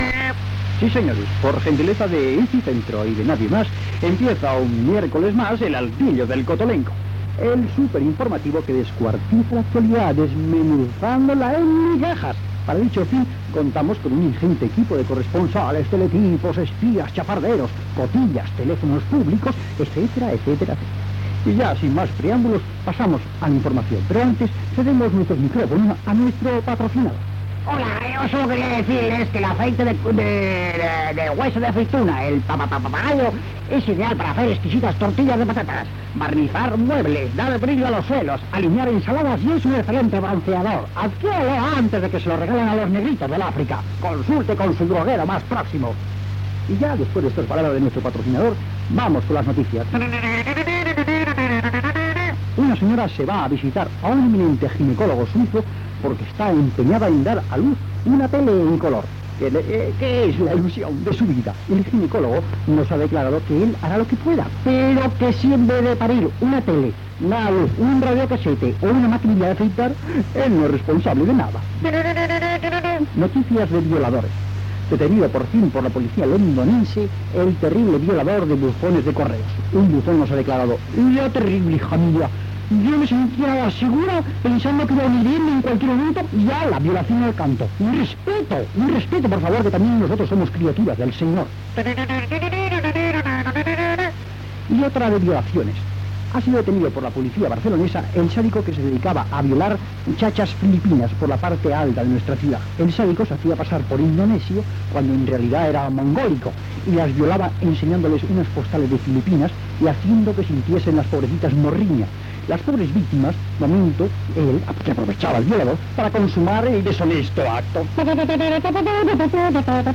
"El altillo del cotolengo", informatiu humorístic. Publicitat
Entreteniment
FM